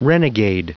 Prononciation du mot renegade en anglais (fichier audio)
Prononciation du mot : renegade